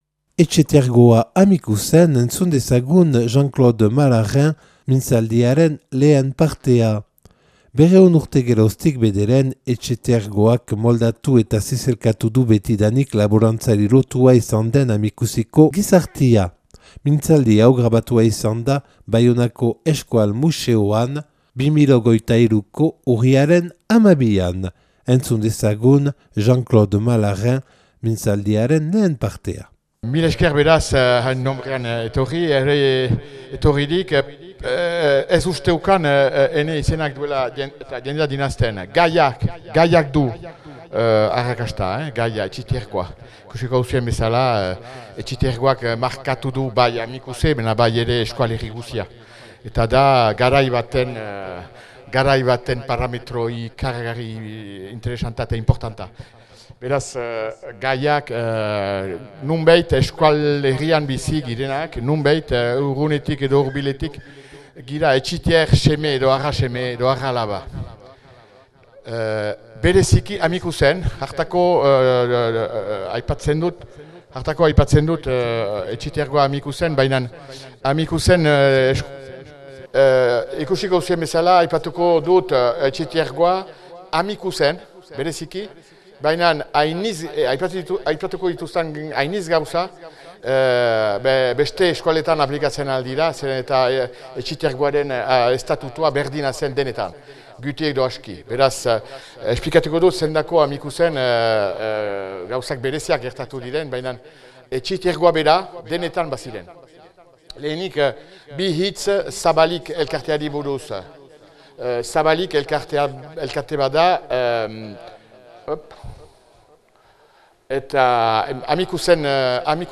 (Euskal Museoan grabatua 2023. Urriaren 12an).